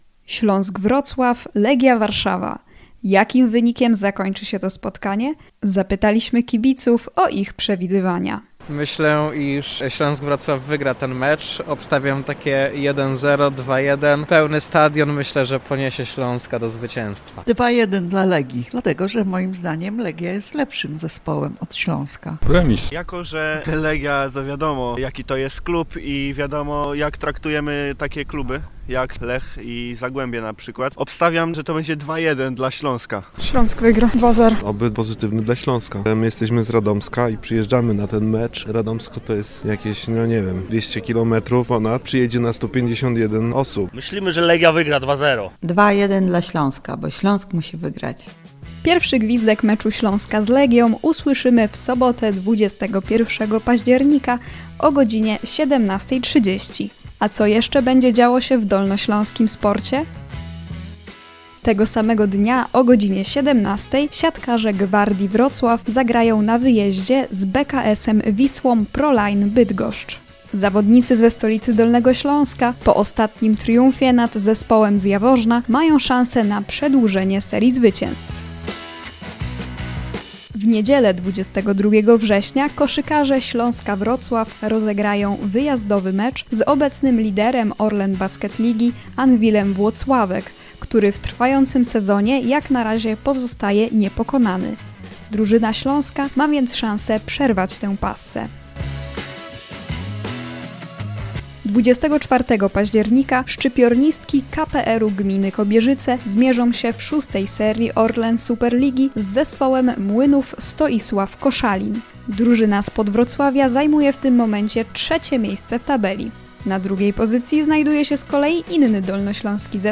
cz.3-sonda-i-zapowiedzi.mp3